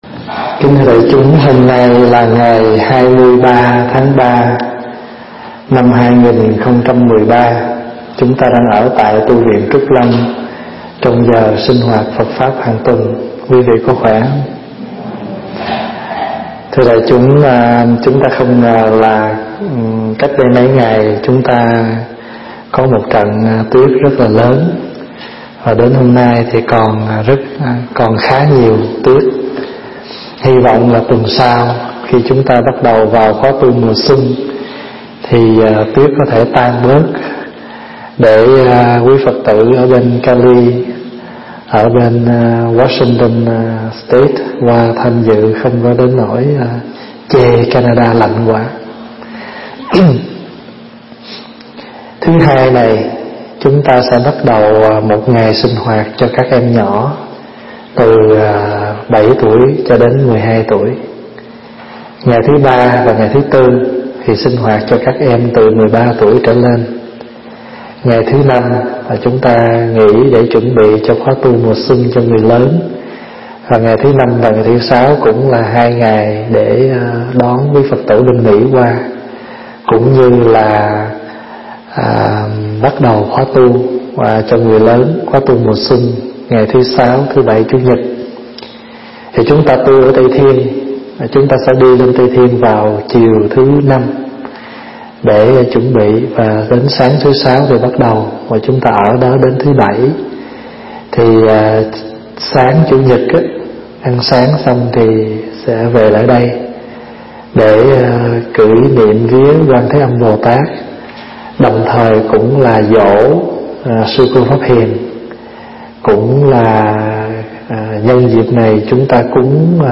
thuyết giảng tại Tu Viện Trúc Lâm, Canada